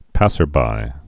(păsər-bī, -bī)